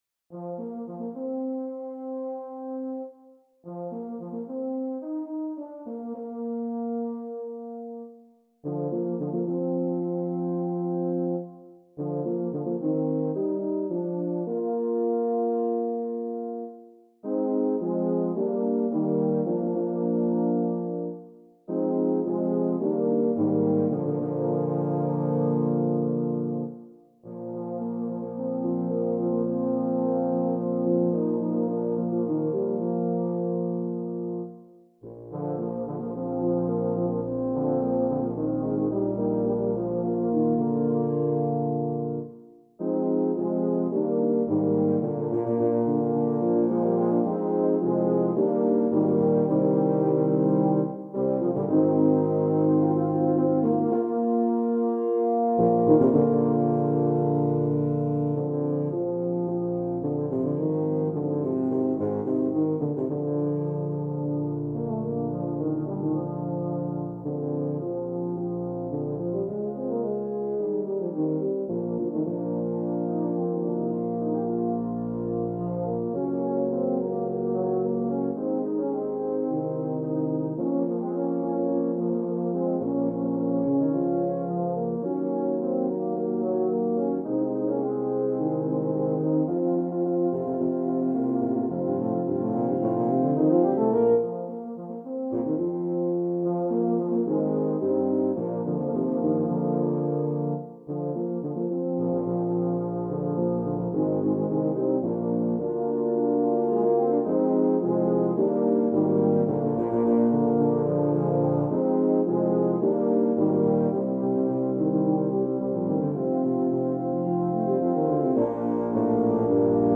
Voicing: Tuba Quartet (EETT)